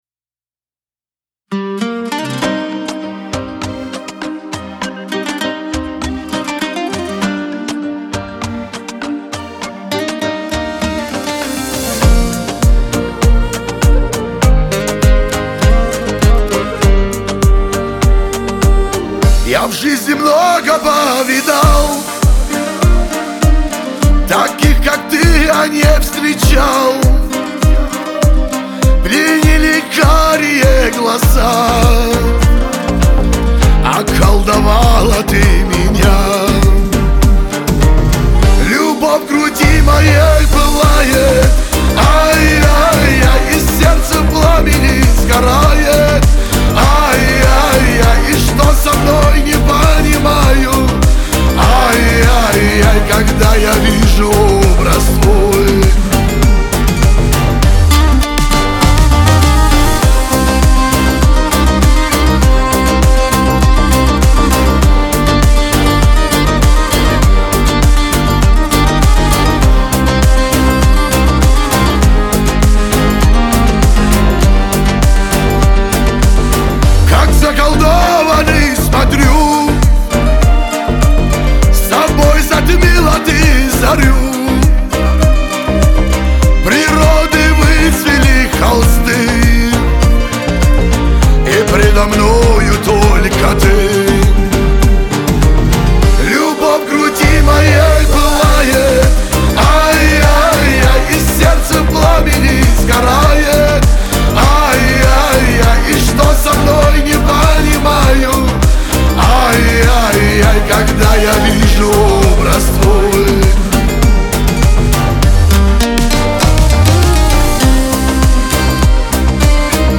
Кавказ поп , грусть